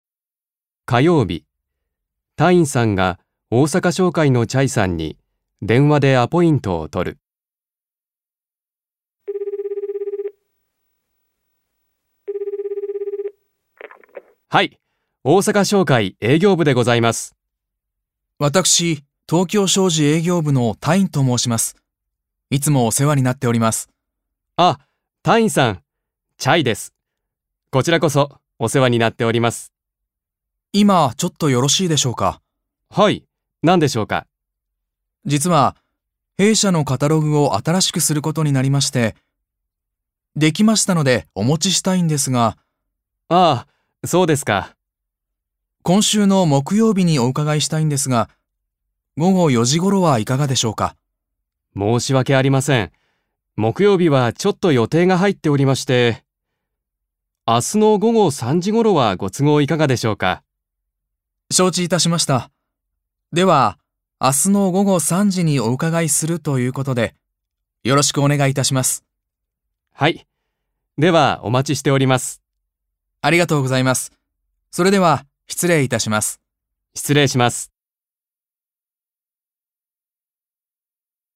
1. 会話
場面：火曜日、タインさんが大阪商会おおさかしょうかいのチャイさんに電話でアポイントを取る。